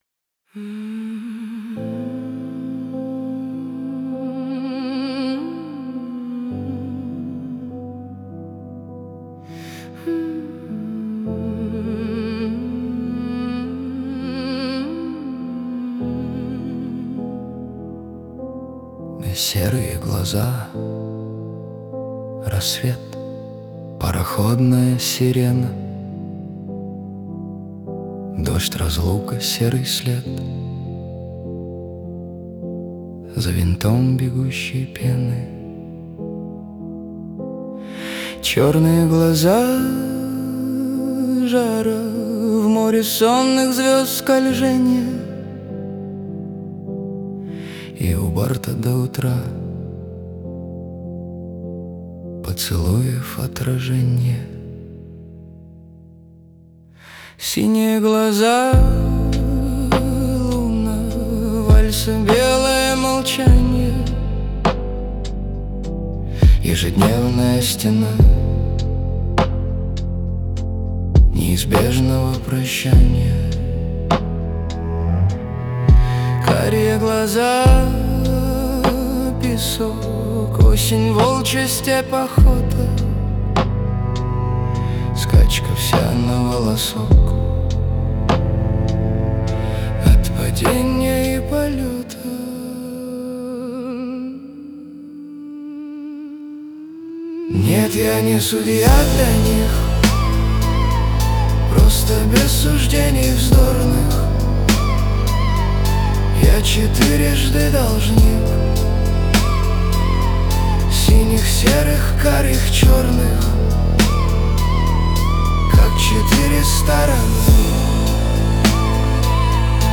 Лаунж